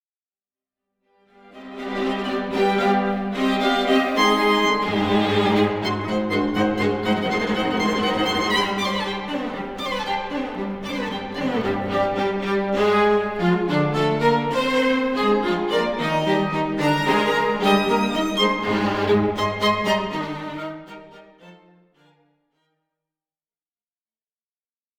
Largo (1.29 EUR)